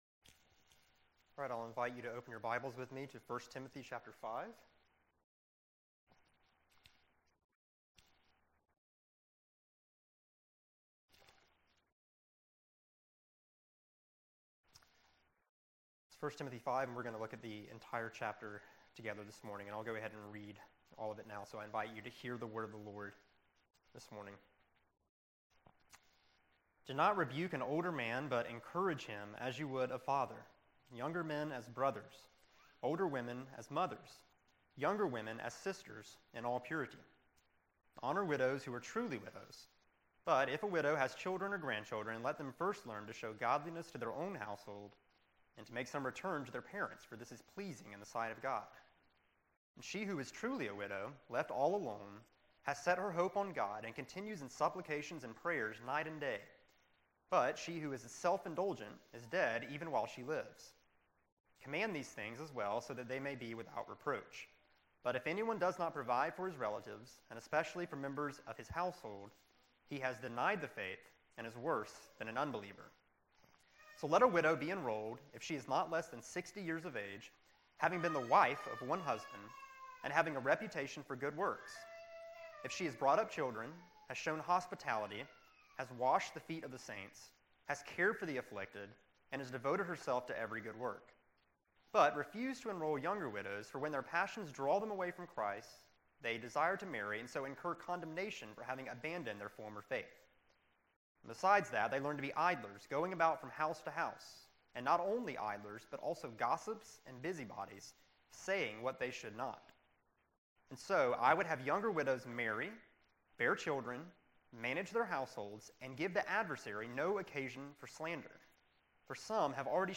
March 6, 2016 Morning Worship | Vine Street Baptist Church